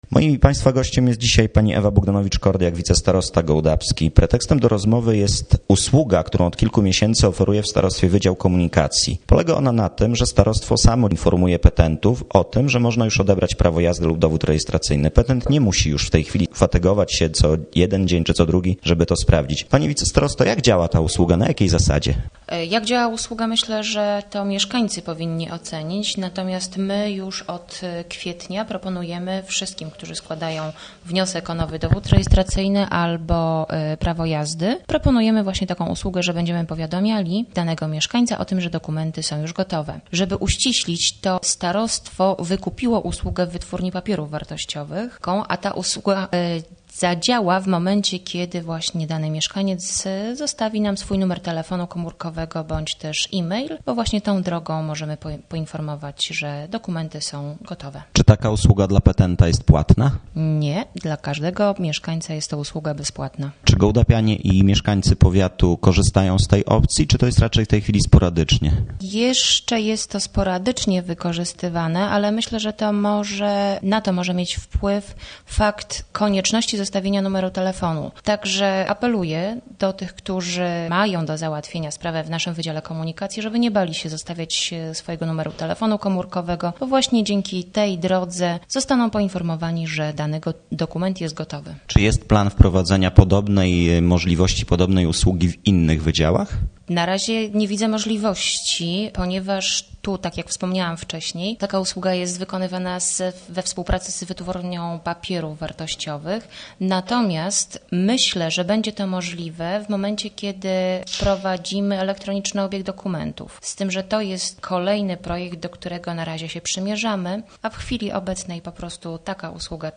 rozmowa z wicestarostą gołdapskim